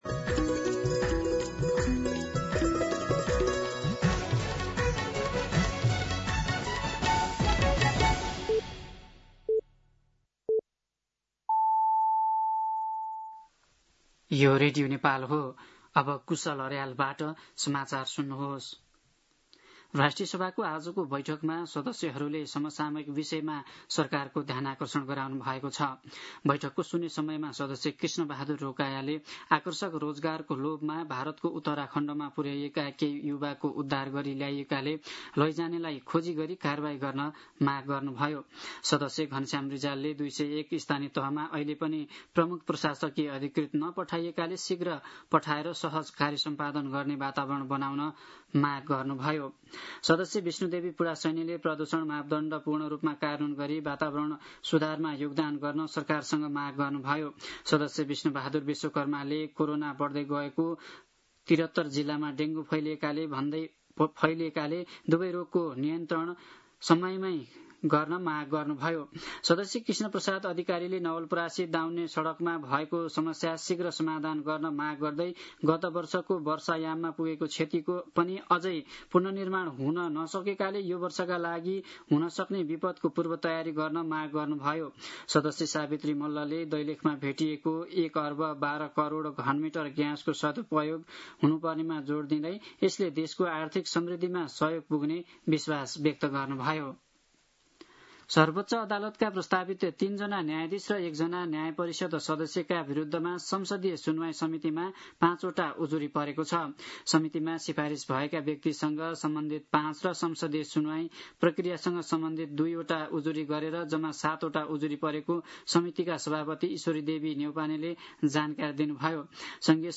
दिउँसो ४ बजेको नेपाली समाचार : १२ असार , २०८२